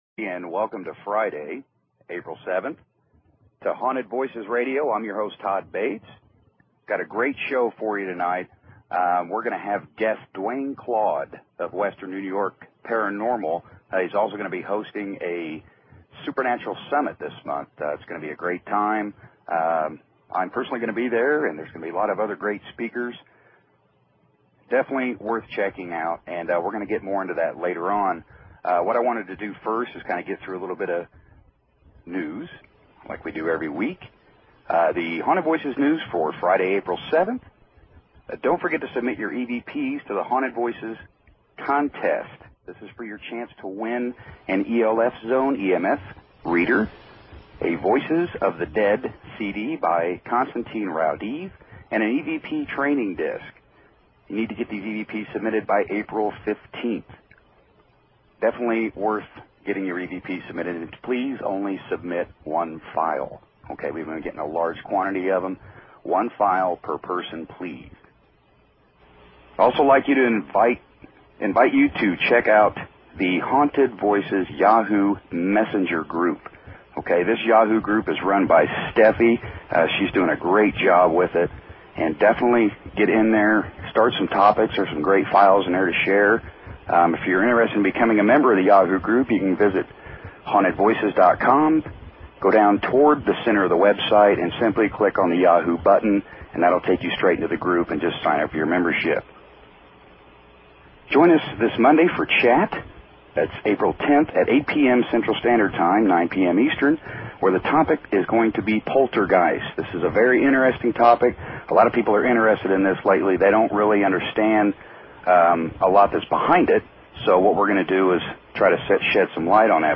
Talk Show Episode, Audio Podcast, Haunted_Voices and Courtesy of BBS Radio on , show guests , about , categorized as